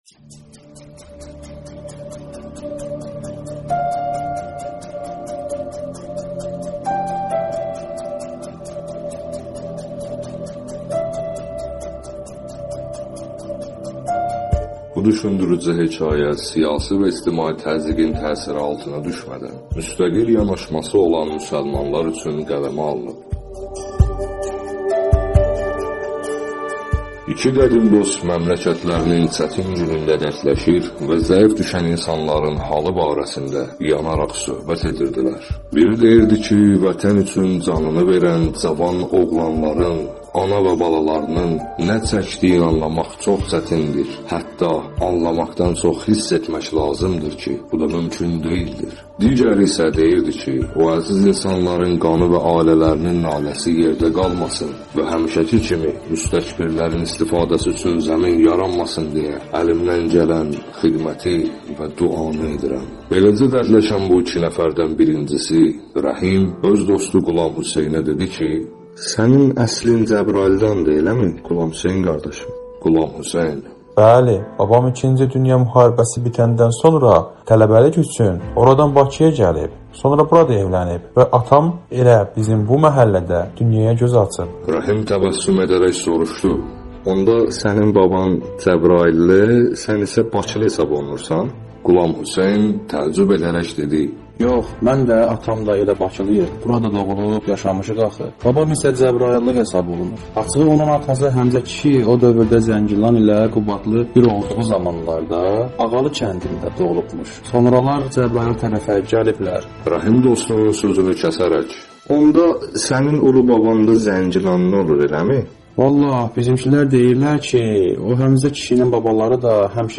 səsli kitab I İslam Bütün İdeologiyaların Fövqündədir | Azərbaycan Araşdırma Mərkəzi